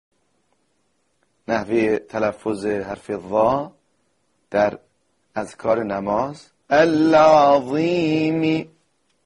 هنگام تلفظ حرف «ظ» ٬ مانند حرف «ذ» سر زبان به پشت سر دندان های پیشین بالا برخورد می کند.
تمرین عملی_مرحله ۴